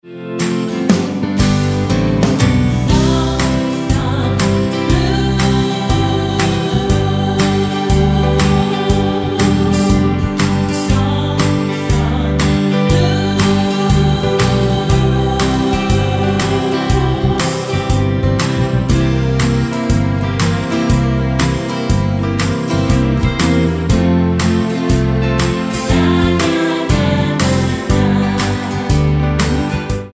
Tonart:C mit Chor
Die besten Playbacks Instrumentals und Karaoke Versionen .